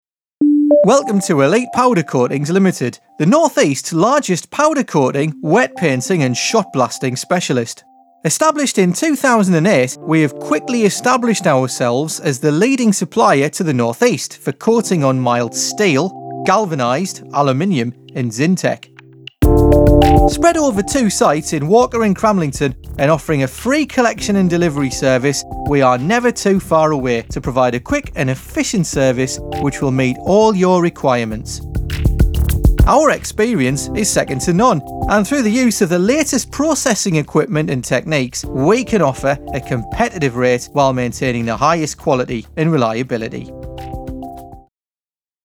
English Male Voice Overs for On Hold Messaging
Accent: North-Eastern/Geordie
Tone / Style: Warm, friendly, upbeat, trustworthy and fairly young-sounding